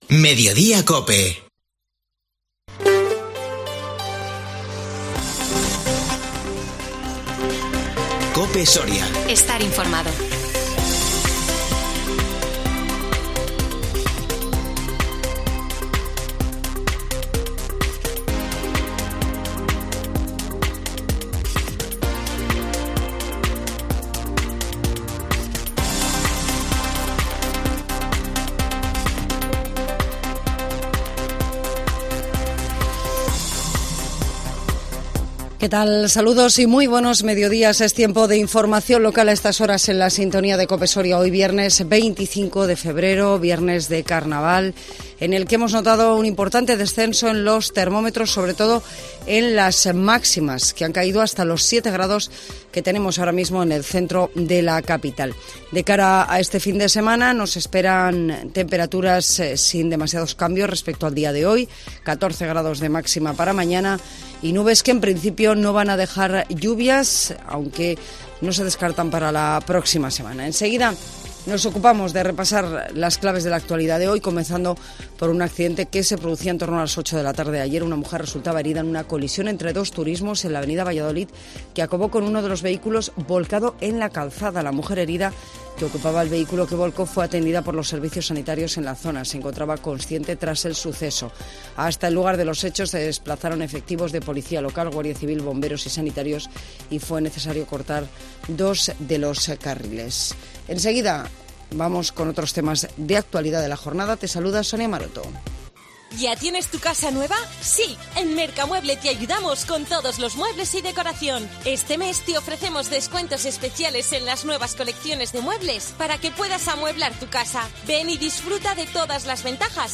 INFORMATIVO MEDIODÍA COPE SORIA 25 FEBRERO 2022